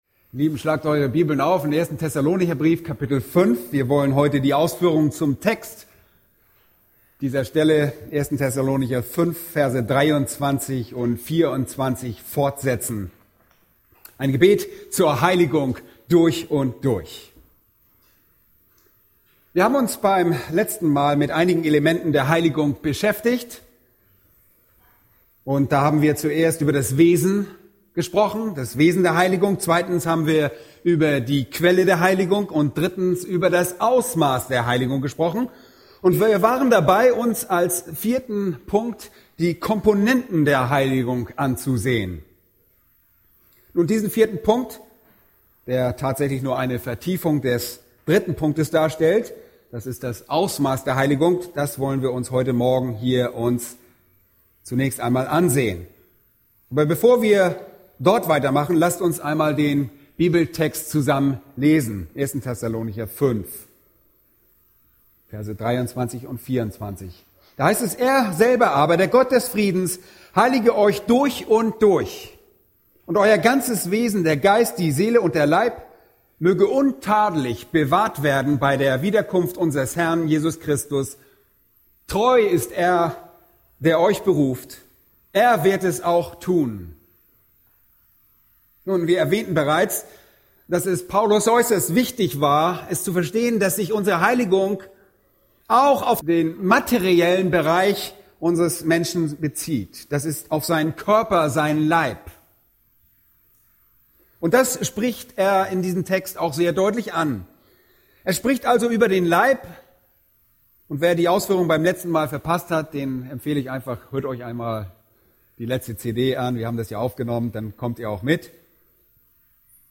A predigt from the serie "Weitere Predigten." Titus 2, 11-15